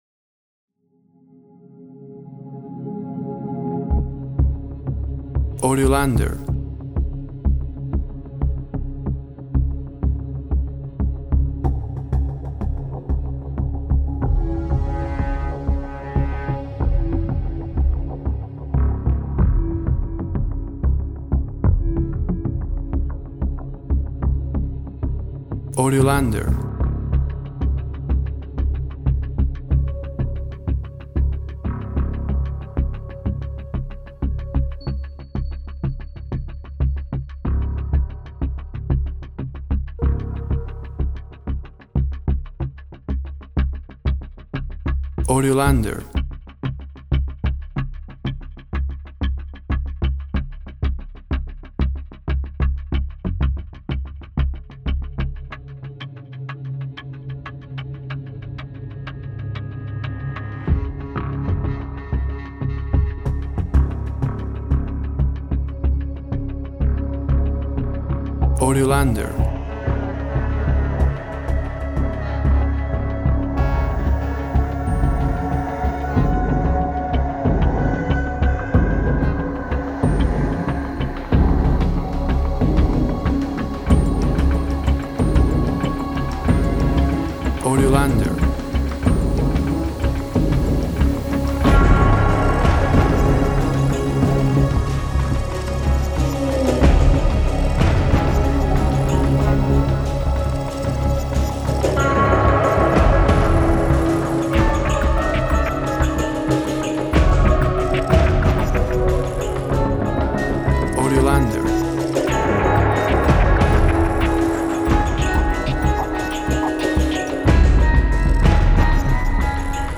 WAV Sample Rate 24-Bit Stereo, 44.1 kHz
Tempo (BPM) 93